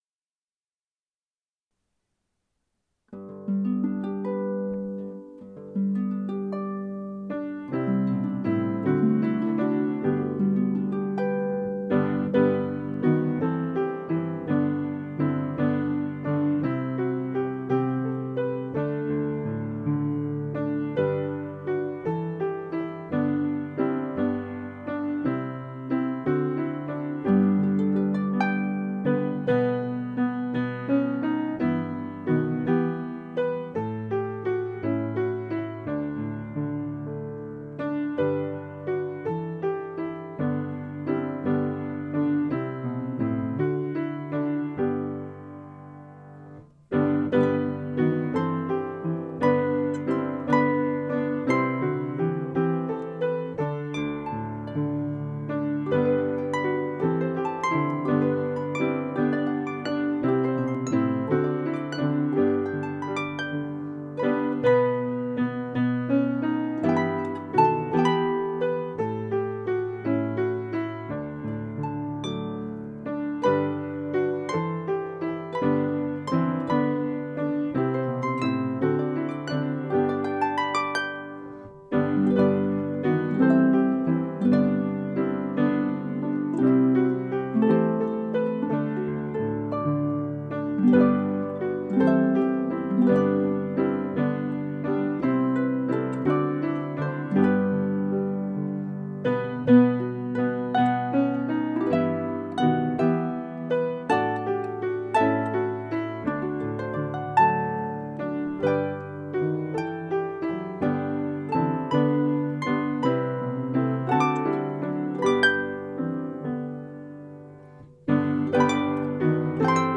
Here are Christmas songs for corporate singing.
harp + piano (4 verses: lowered key G)Download